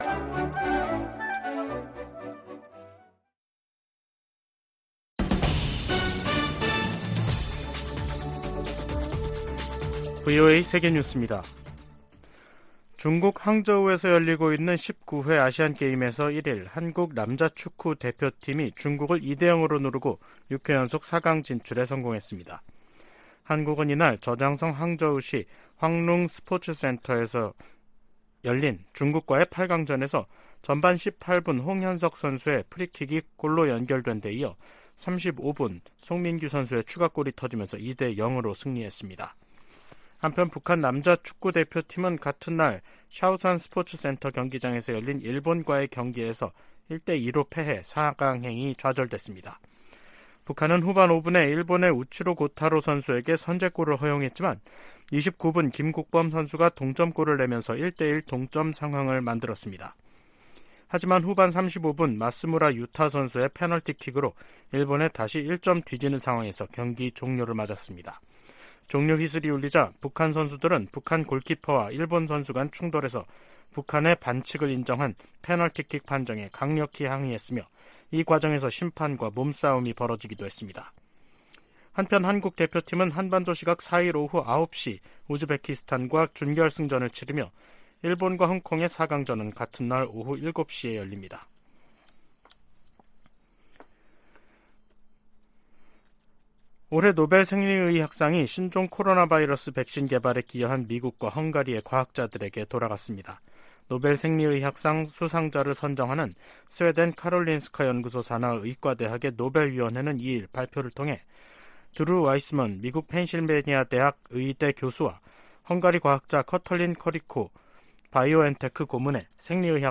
VOA 한국어 간판 뉴스 프로그램 '뉴스 투데이', 2023년 10월 2일 3부 방송입니다. 국제원자력기구(IAEA)는 오스트리아에서 열린 제67차 정기총회에서 북한의 지속적인 핵 개발을 규탄하고, 완전한 핵 폐기를 촉구하는 결의안을 채택했습니다. 북한이 핵 보유국 지위를 부정하는 국제사회 비난 담화를 잇달아 내놓고 있습니다.